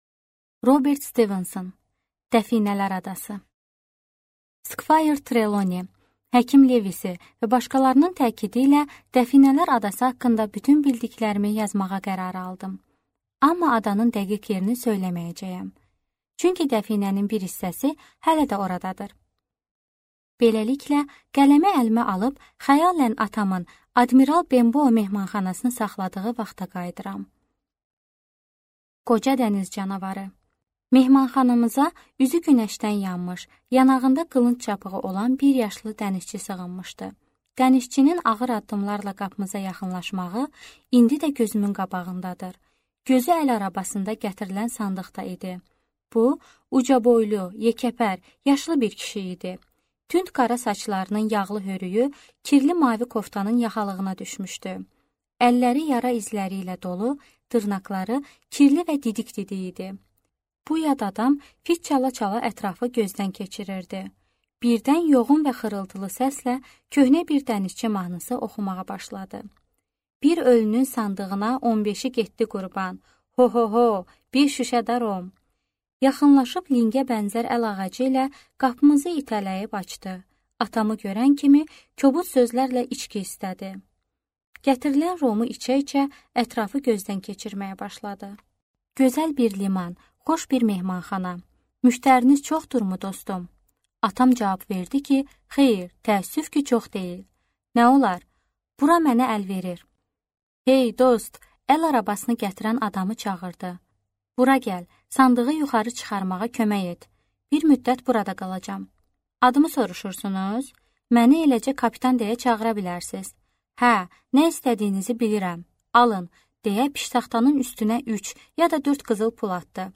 Аудиокнига Dəfinələr adası | Библиотека аудиокниг